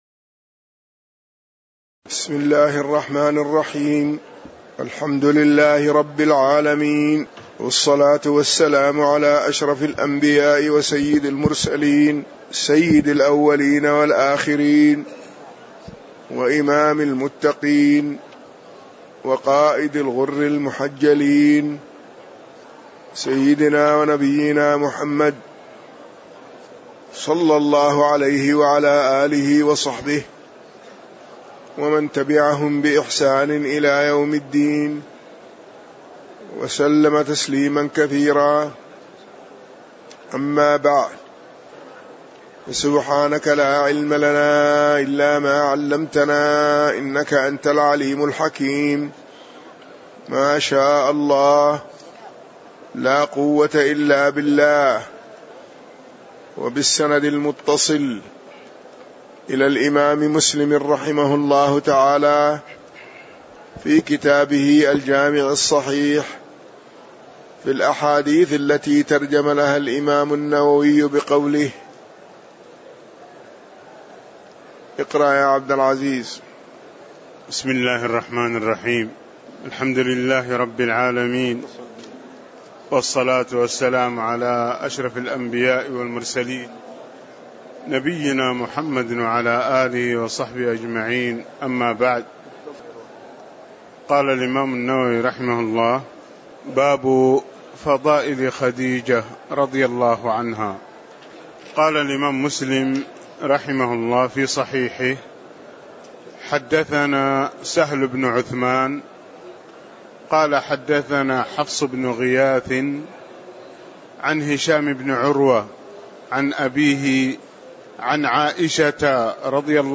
تاريخ النشر ٢٣ شعبان ١٤٣٧ هـ المكان: المسجد النبوي الشيخ